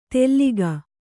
♪ telliga